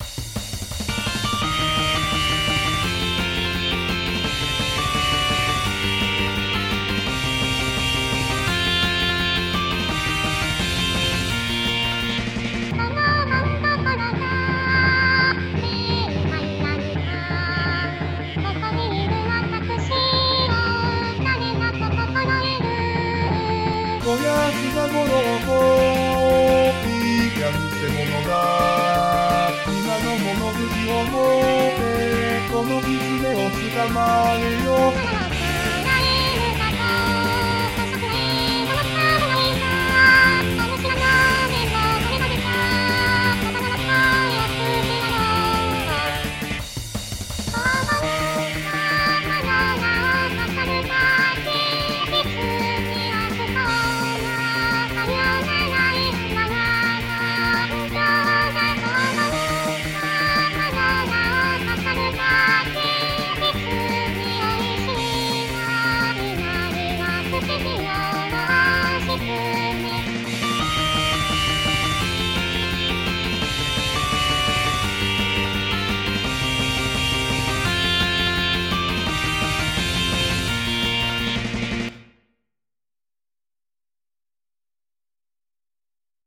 盆踊り